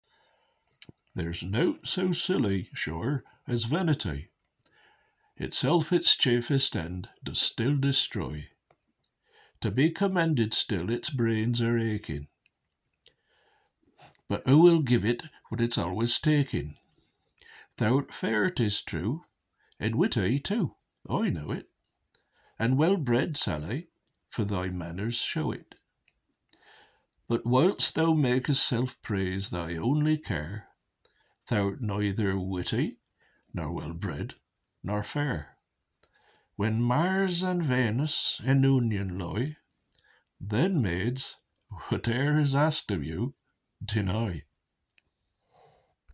Readings from Franklin’s Poor Richard’s Almanack and his Reformed Mode of Spelling